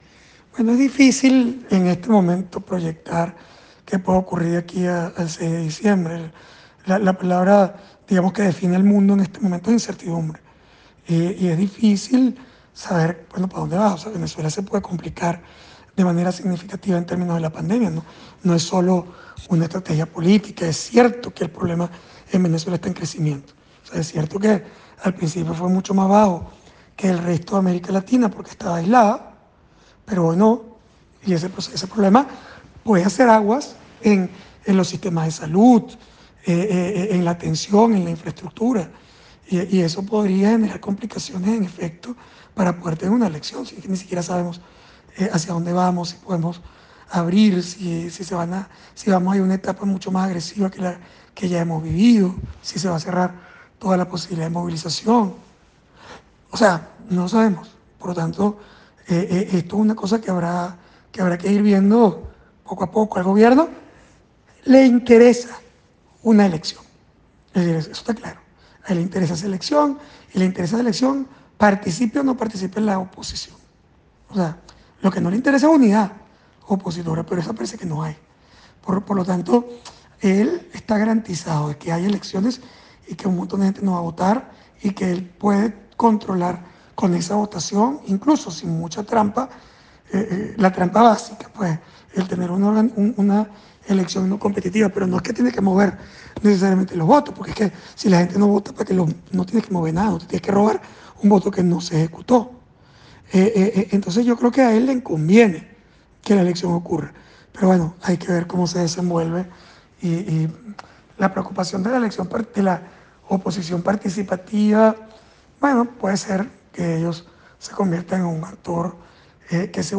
Las preguntas no las respondió por escrito, ni en conversación telefónica, sino que remitió seis notas de voz.